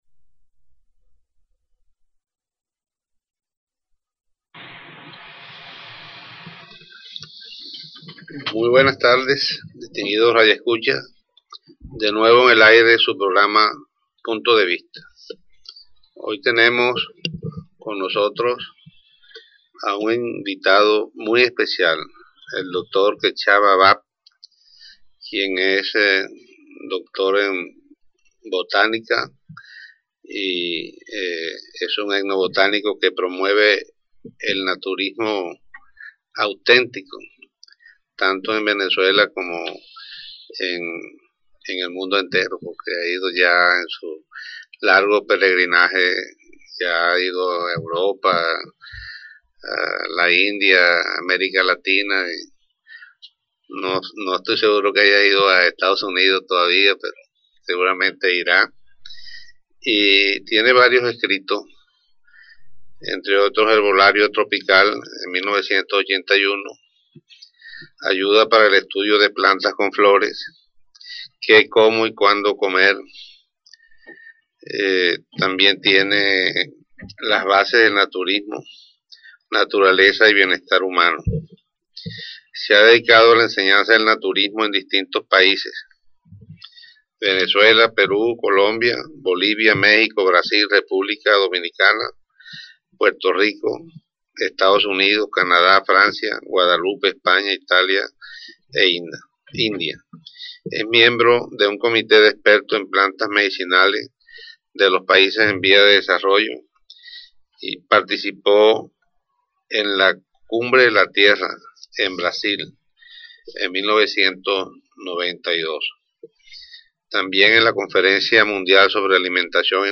ENTREVISTA_PROGRAMA_PUNTO_DE_VISTA.mp3